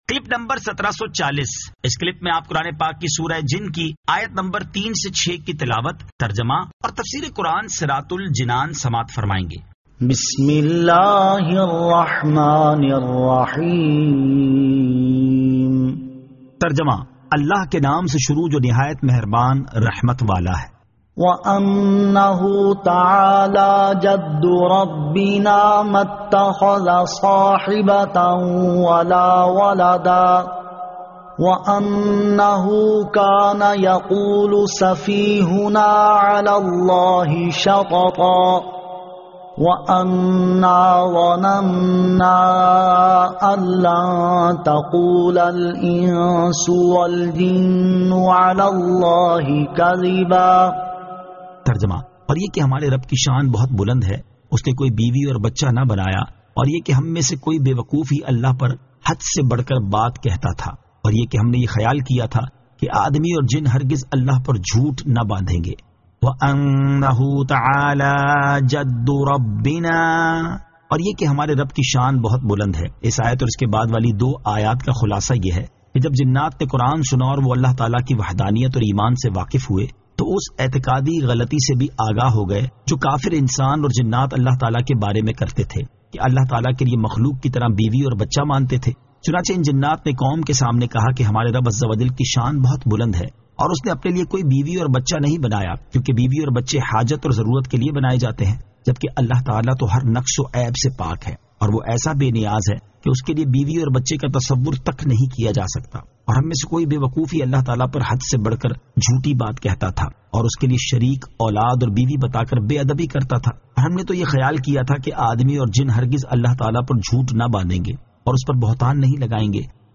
Surah Al-Jinn 03 To 06 Tilawat , Tarjama , Tafseer